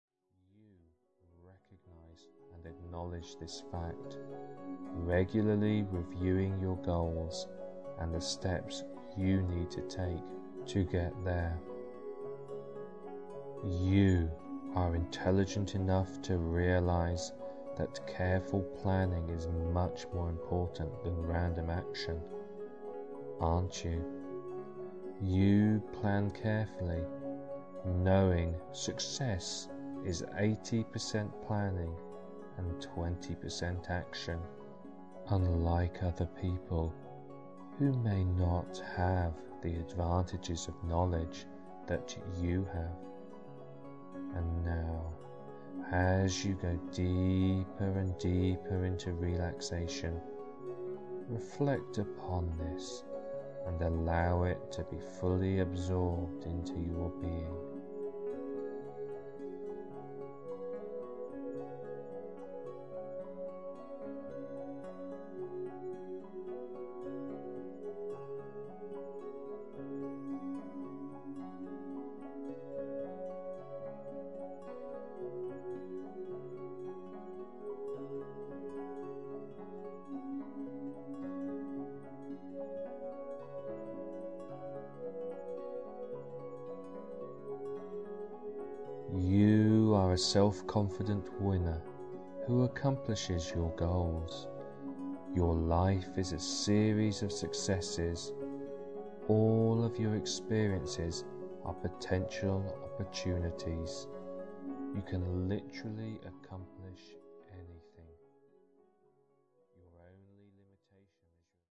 Achieving Your Goals Vocal Hypnosis MP3